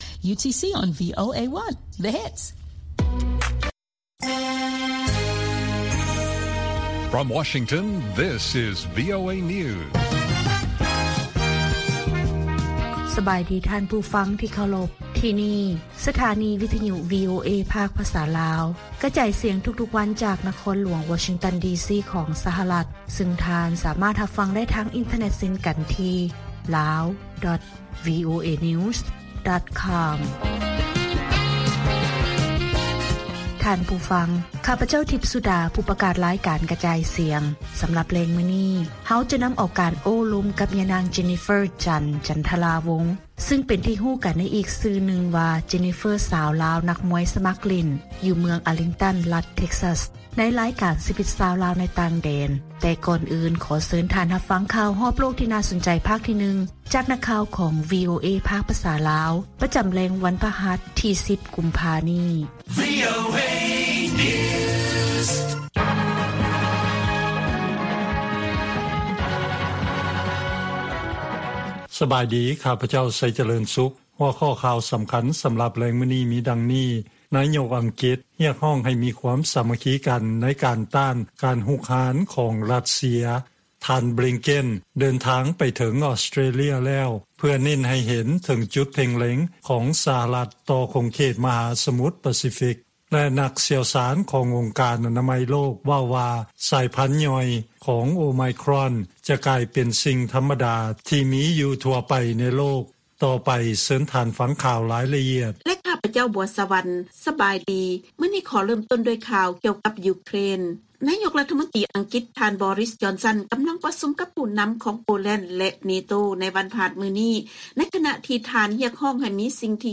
ລາຍການກະຈາຍສຽງຂອງວີໂອເອ ລາວ: ນາຍົກອັງກິດຮຽກຮ້ອງໃຫ້ມີຄວາມສາມັກຄີກັນໃນການຕ້ານ ’ການຮຸກຮານຂອງຣັດເຊຍ’